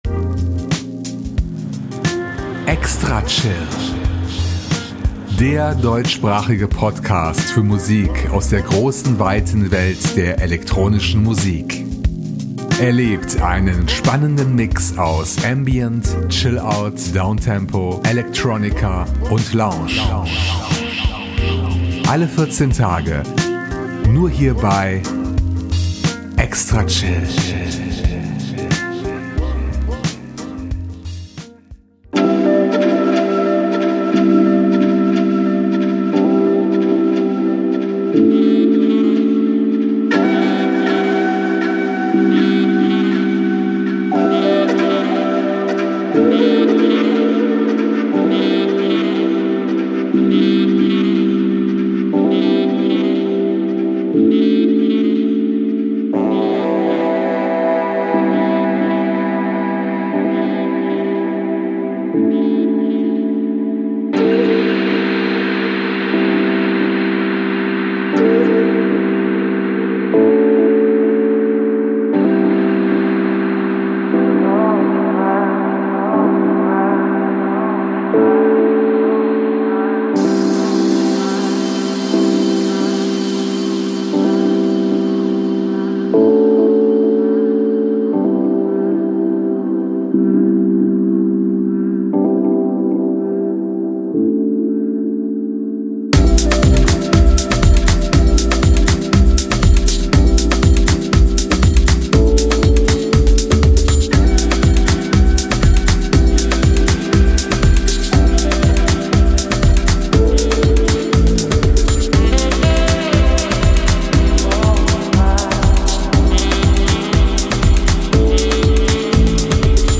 an extended playlist of ten podsafe electronica tracks